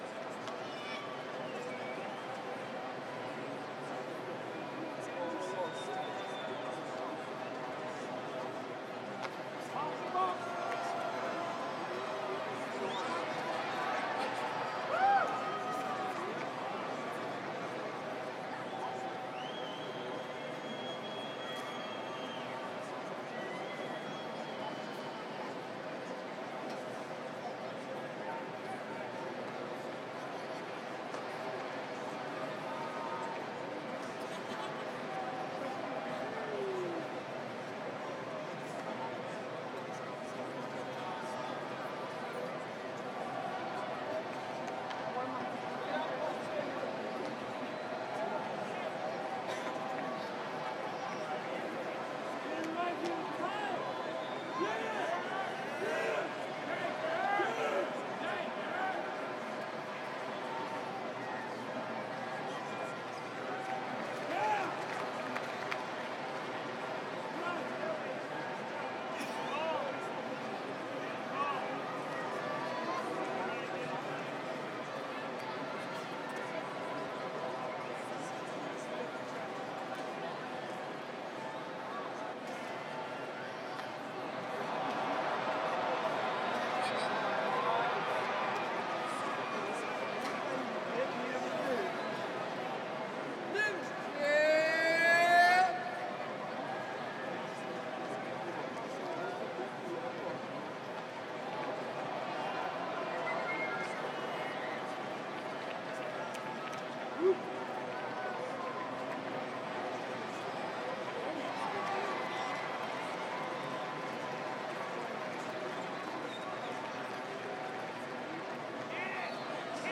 chatter-loop.wav